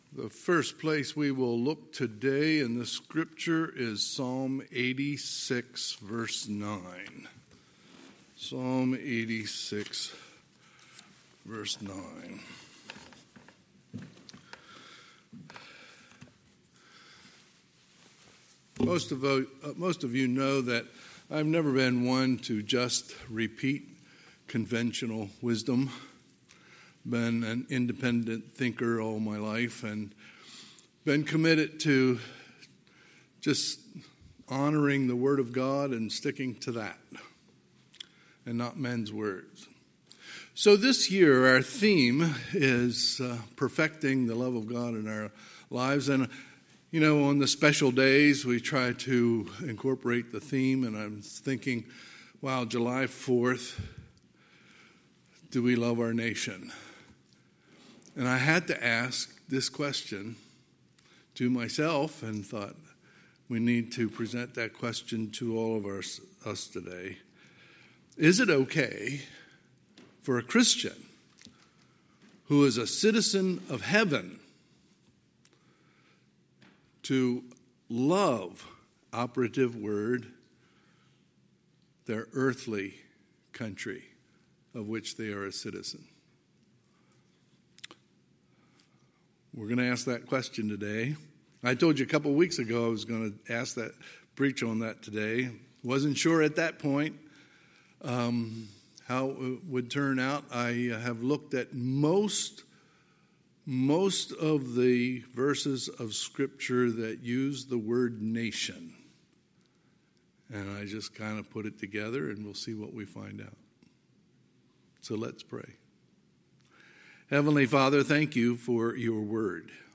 Sunday, July 4, 2021 – Sunday AM